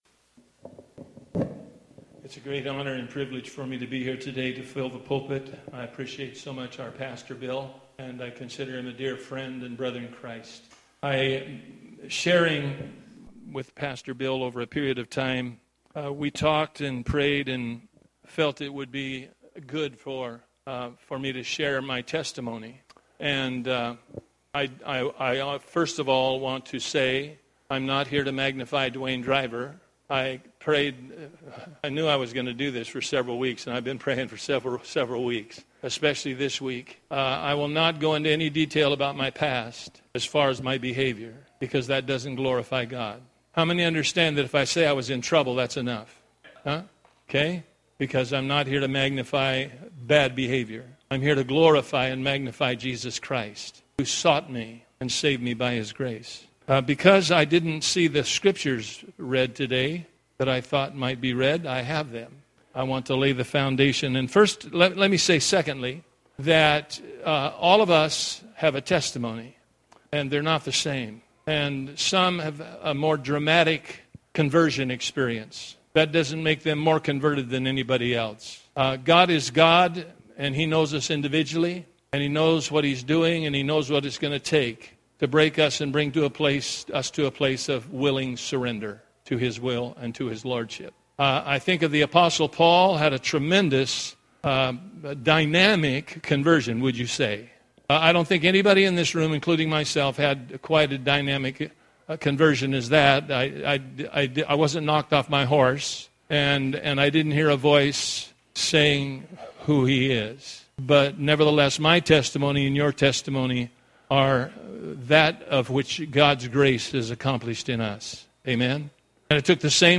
Testimony Service Type: Sunday Morning Preacher